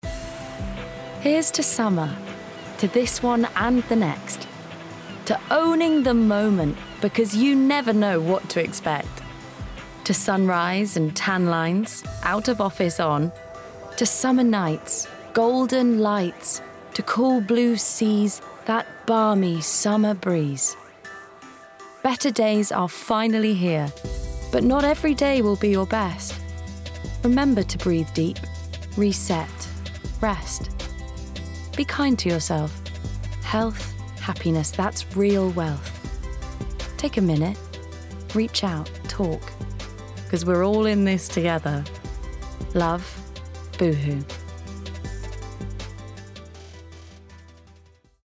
20/30's Neutral/West Country,
Modern/Fresh/Engaging